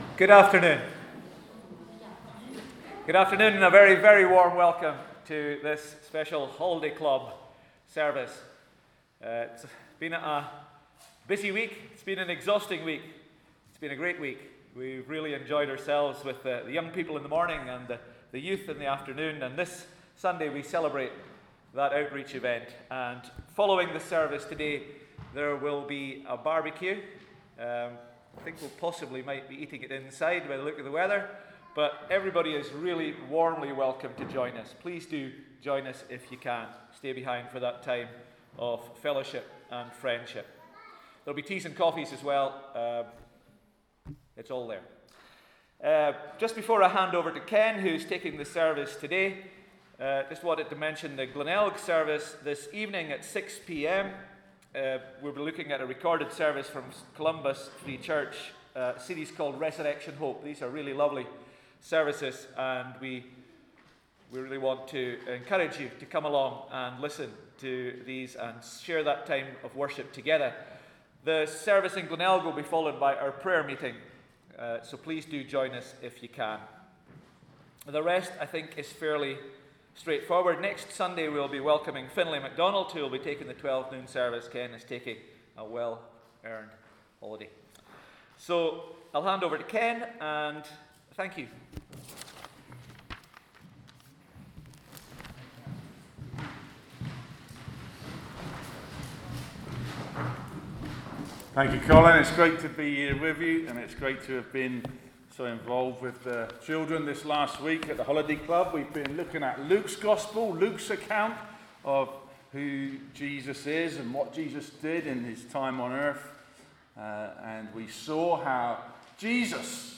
Sunday Service 24th July 2022